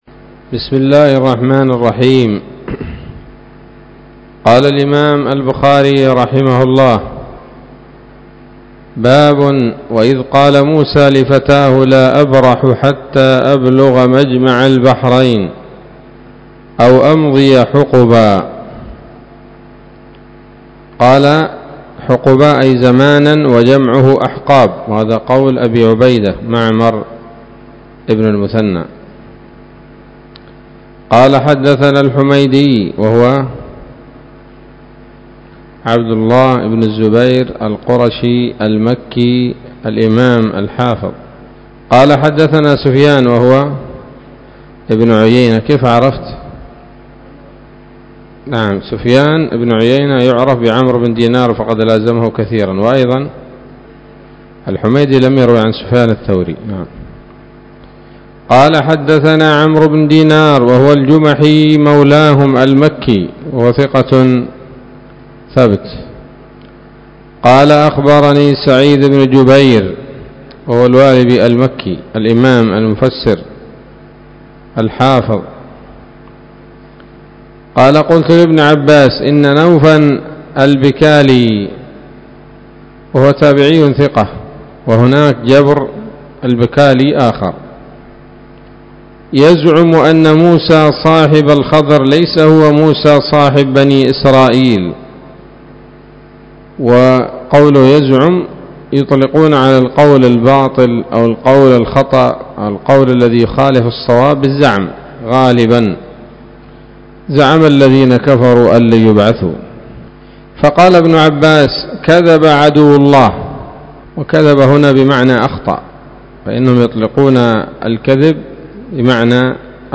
الدرس الثالث والستون بعد المائة من كتاب التفسير من صحيح الإمام البخاري